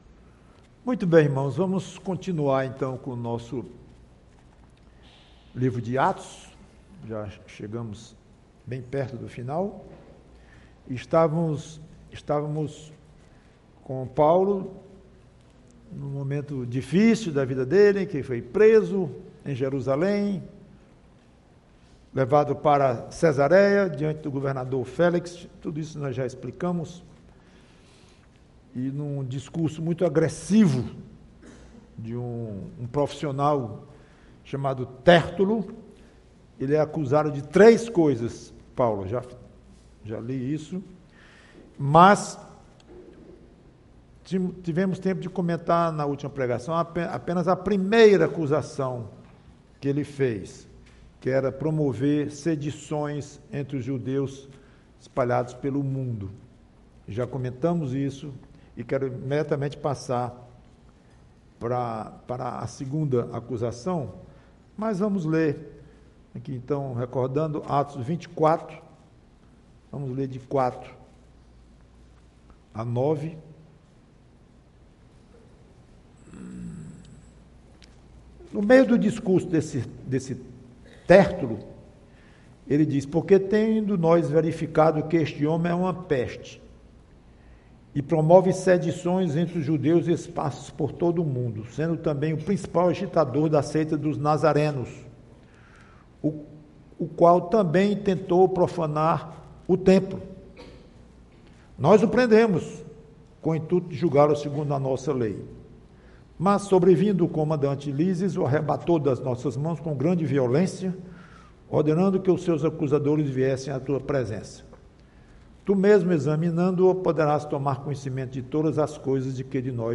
PREGAÇÃO Teólogo, Evangelista, Pregador e...